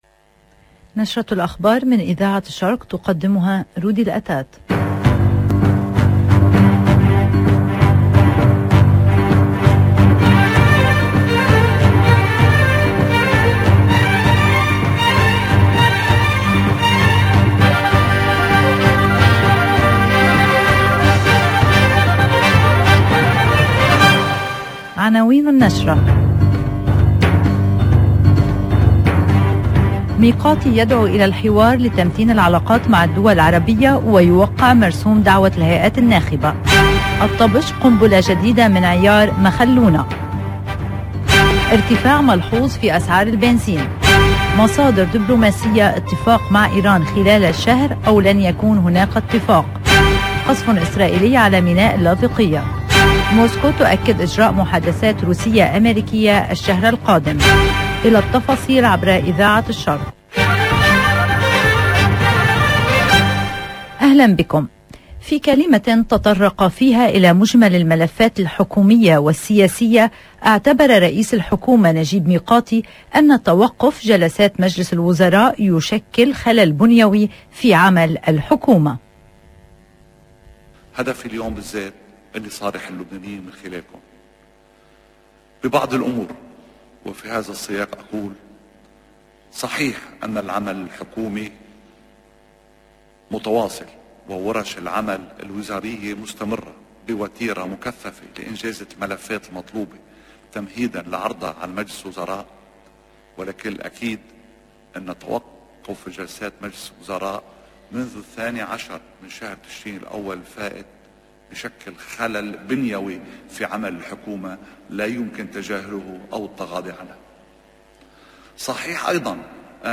LE JOURNAL DE 13H30 DU LIBAN EN LANGUE ARABE DU 28/12/2021